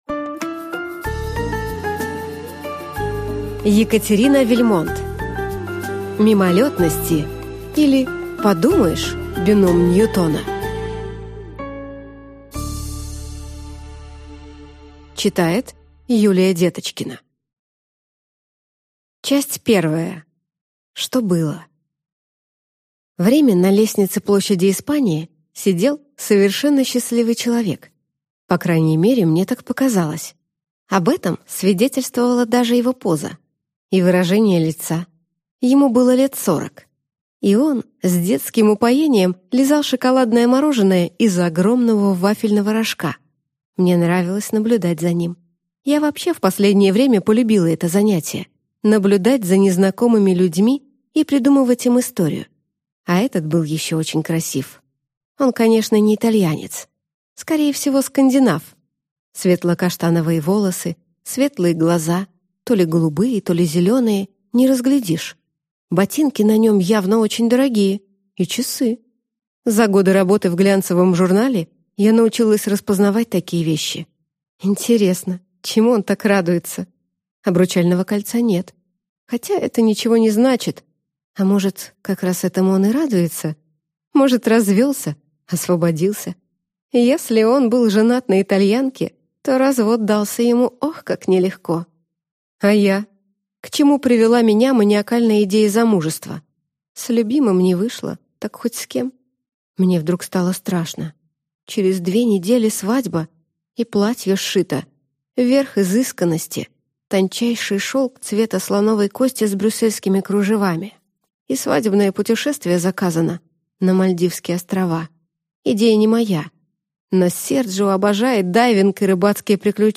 Аудиокнига Мимолетности, или Подумаешь, бином Ньютона!